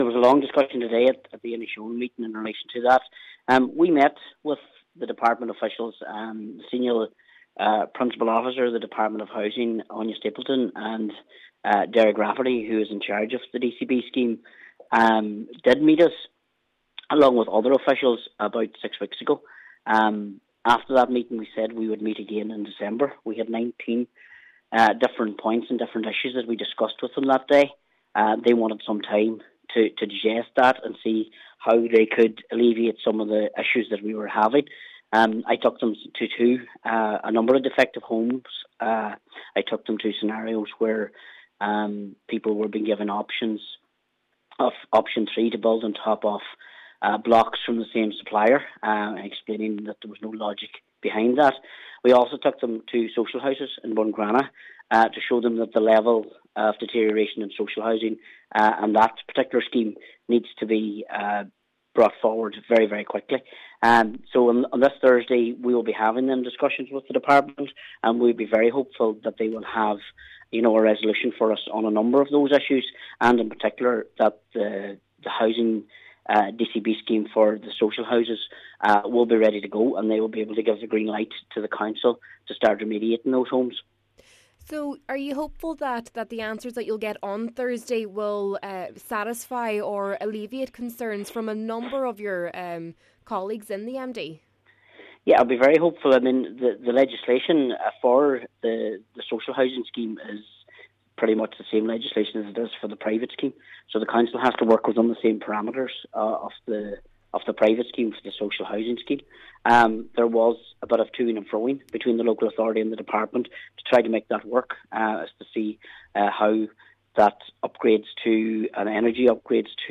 Chairperson of the committee, Cllr Martin McDermott says he is anticipating there will be good new on the social housing front: